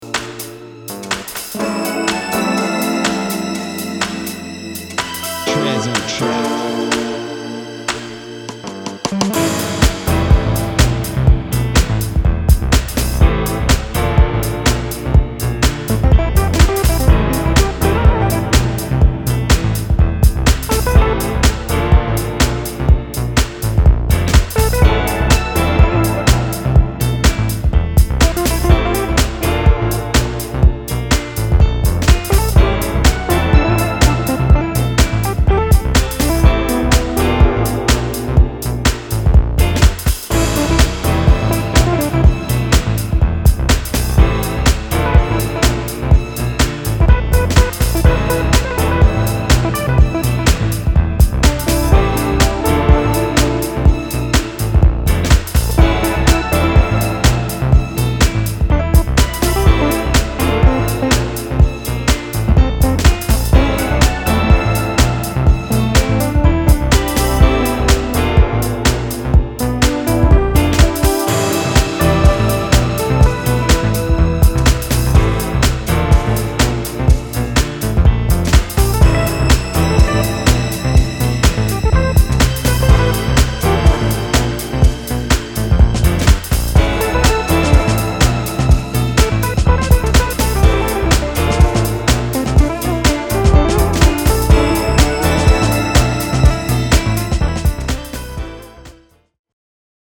ジャジーなテイストやデトロイティッシュな要素を織り込んだクールなディープ・ハウスが入荷！！
ジャンル(スタイル) DEEP HOUSE